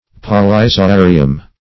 Search Result for " polyzoarium" : The Collaborative International Dictionary of English v.0.48: Polyzoarium \Pol`y*zo*a"ri*um\, n.; pl.